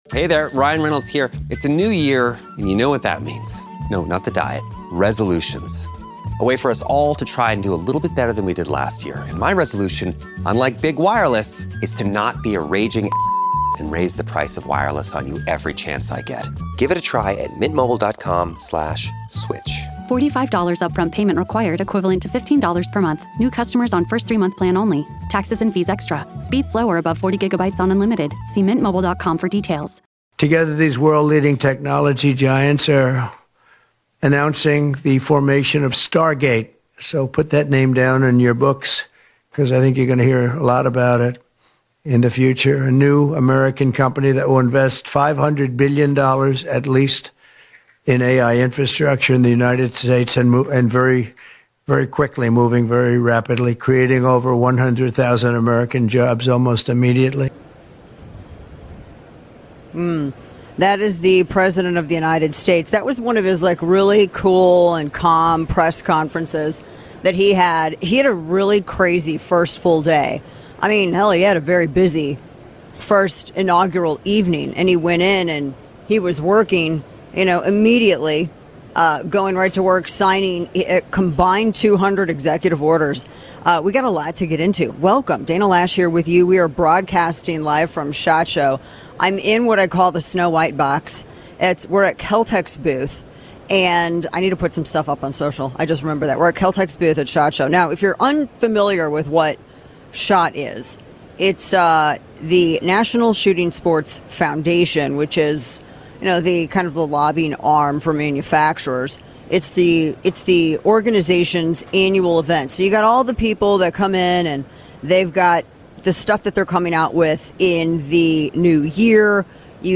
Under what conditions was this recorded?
Live from SHOT Show in Las Vegas.